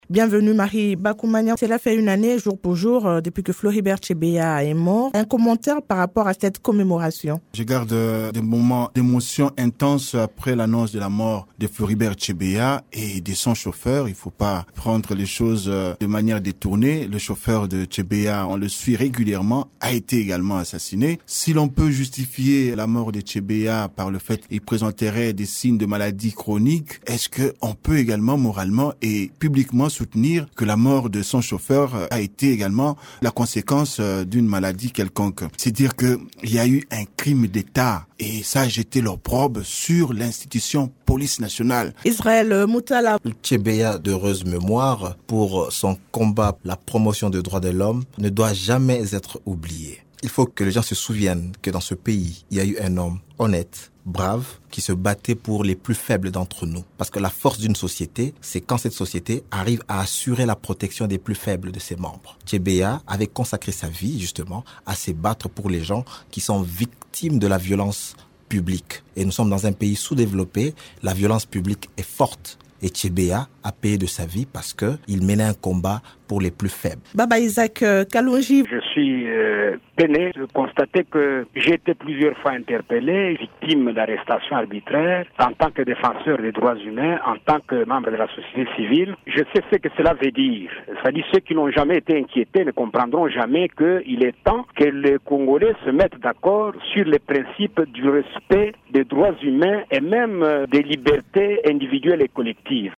Trois thèmes sont au centre de la tribune de la presse de vendredi 3 juin, à savoir: